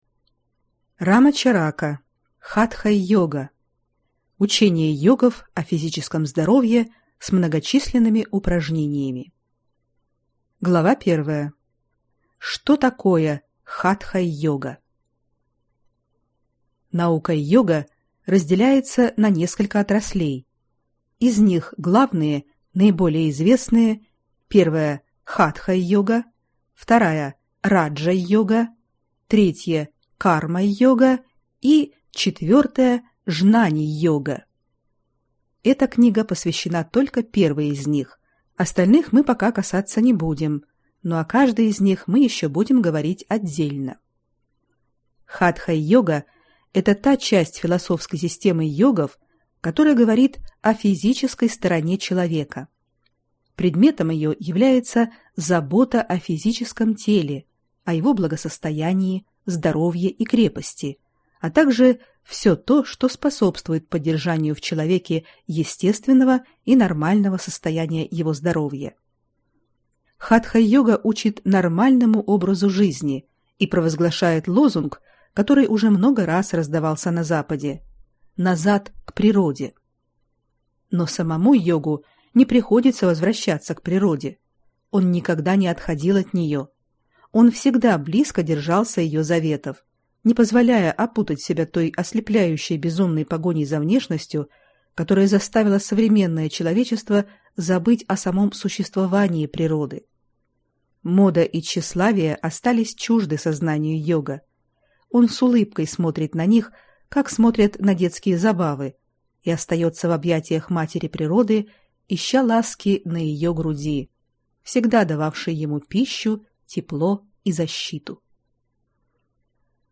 Аудиокнига Хатха-йога | Библиотека аудиокниг